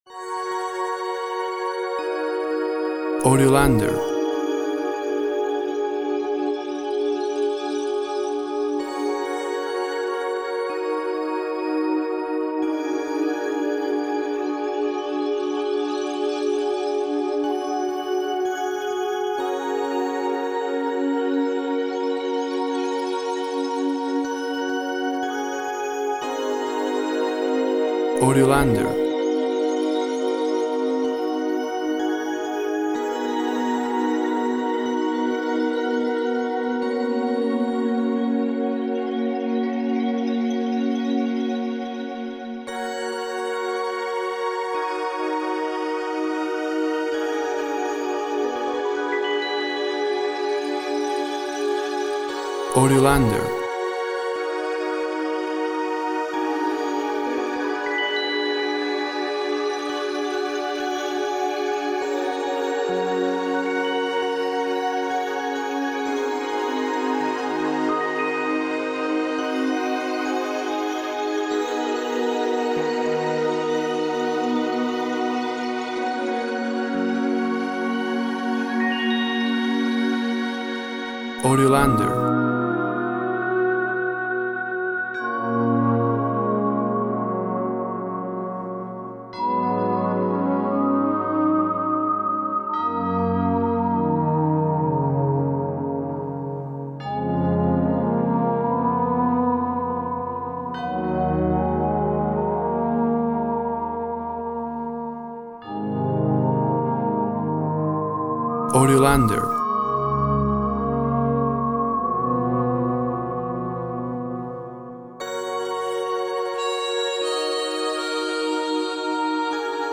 Tempo (BPM) 60